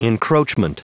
Prononciation du mot encroachment en anglais (fichier audio)
Prononciation du mot : encroachment